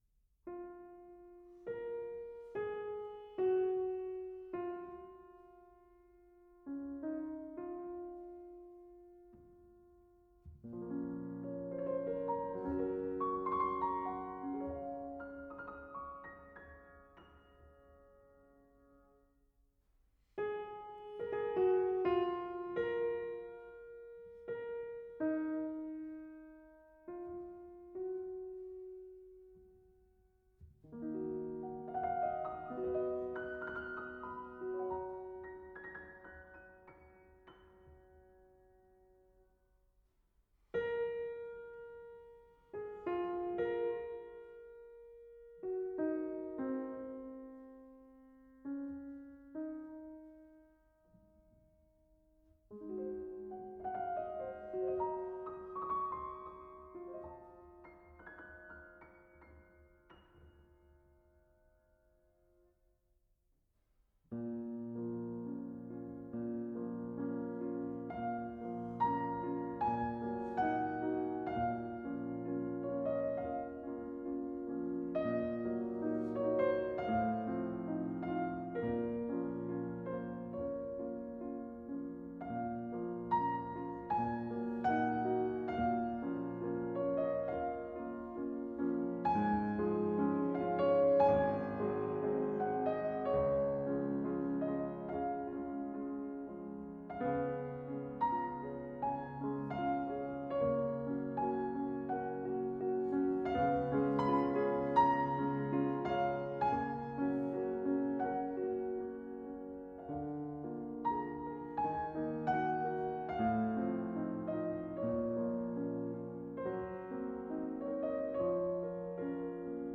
全曲以简单、含蓄的笔法描绘出大自然清新的画面，具有纯朴的俄国民歌风味。
钢琴伴奏模仿云雀清脆的啼鸣、旋律中"b(?)"音的出现增添了作品的色彩和魅力。
歌曲开始由钢琴奏出婉转的鸟鸣，最后也结束在云雀清亮的歌声，前后完整统一，形象鲜明生动。